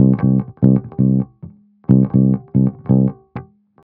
18 Bass Loop C.wav